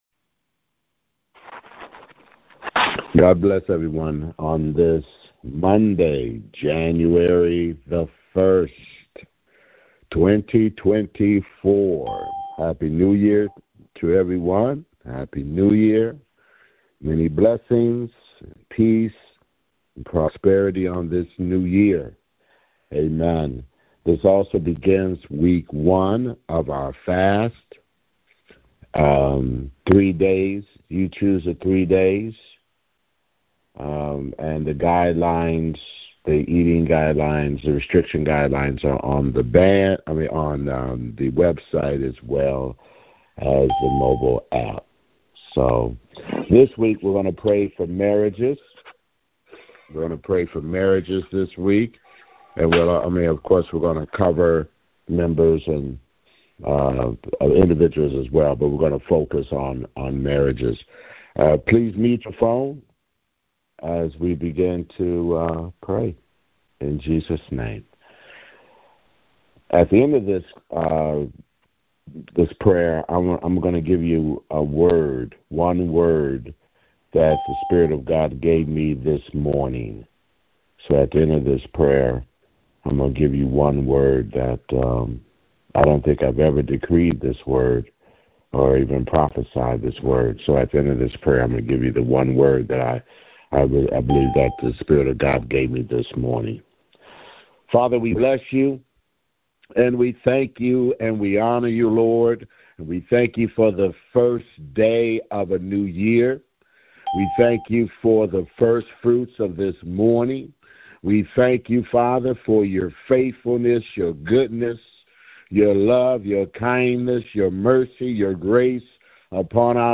Prayers for married Ccouples taken from the weekly prayer conference line.